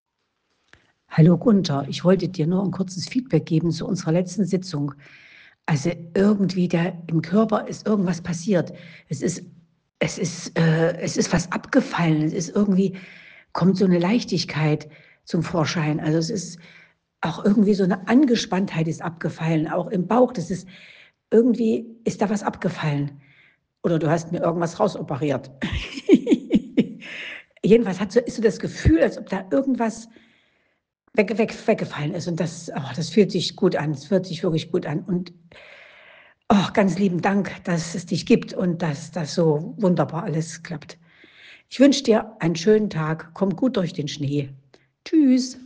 Audiobewertung einer Klientin